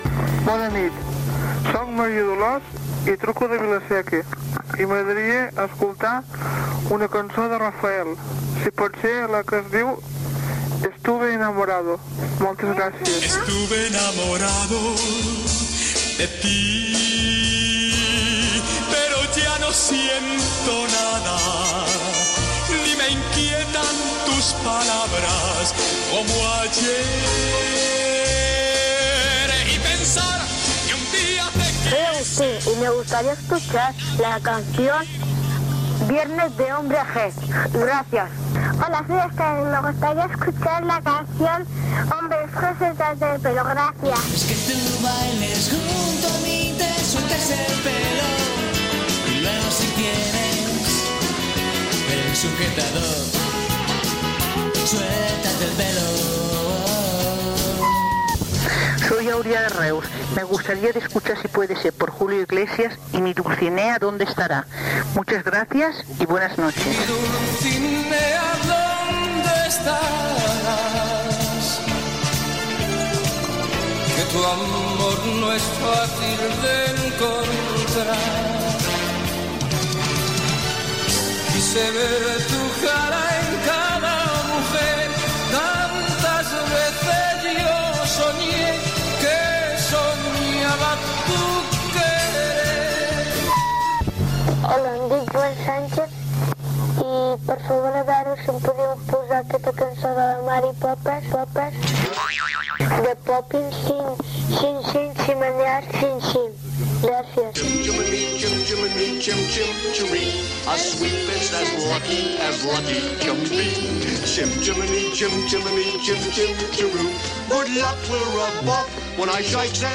Trucades de diverses oïdores demanat cançons